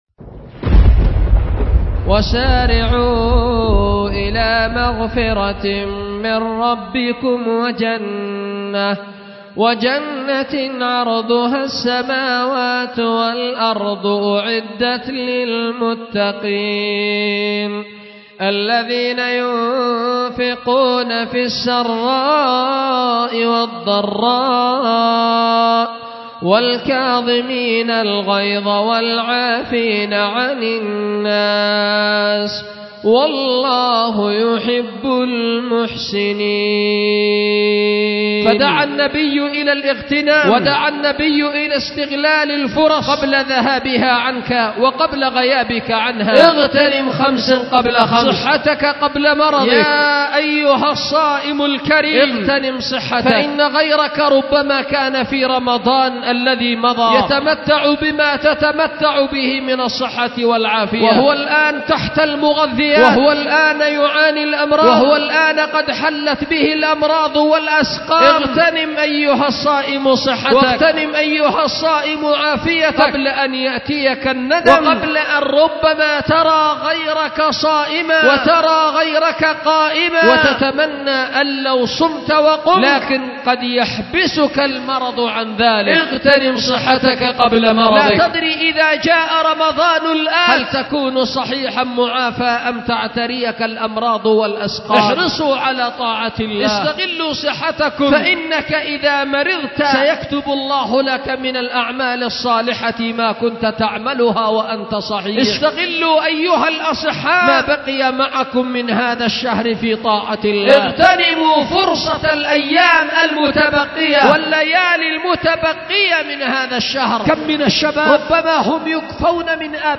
خطبة
أُلقيت بدار الحديث للعلوم الشرعية بمسجد ذي النورين ـ اليمن ـ ذمار